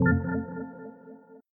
menu-freeplay-click.ogg